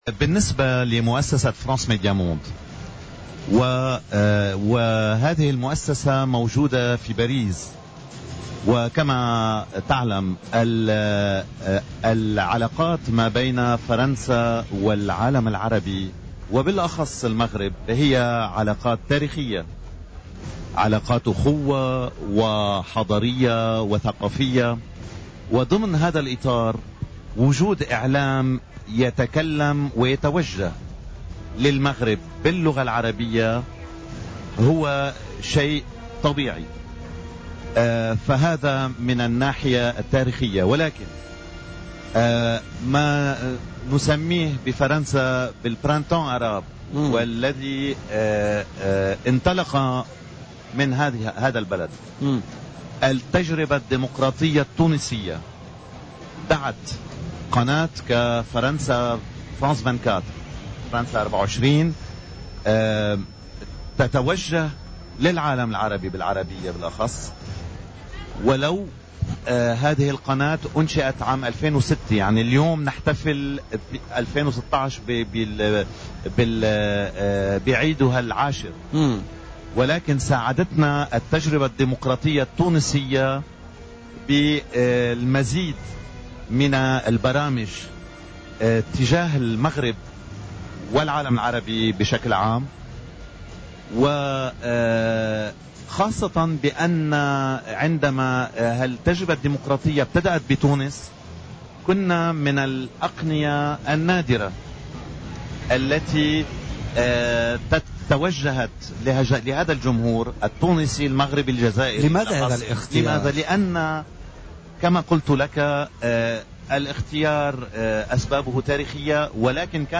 في مداخلة له اليوم في برنامج "بوليتيكا" على هامش فعاليات المهرجان العربي للإذاعة والتلفزيون بالحمامات